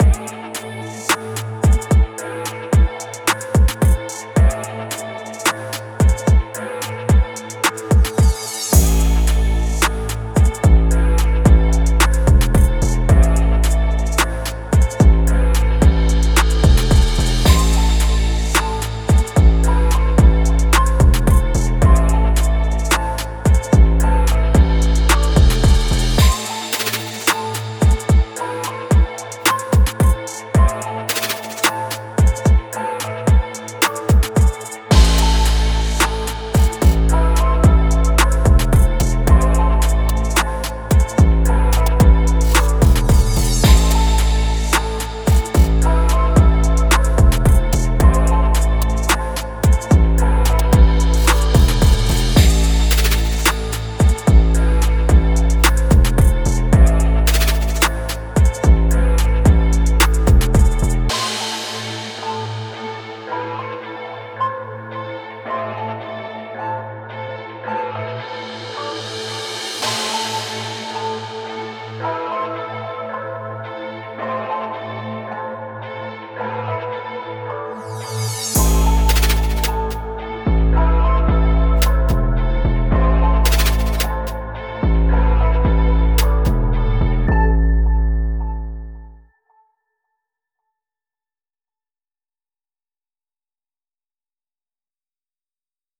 horror-music.mp3